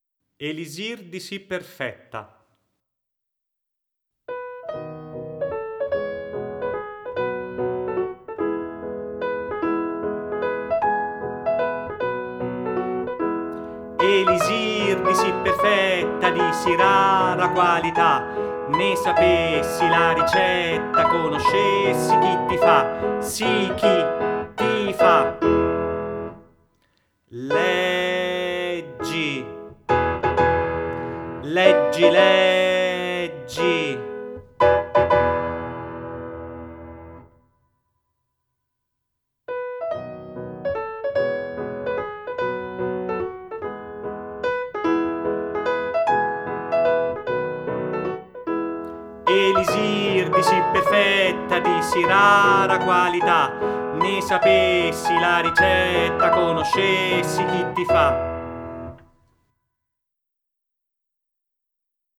06_Elisir di sì perfetta_ritmica
06_Elisir-di-sì-perfetta_ritmica.mp3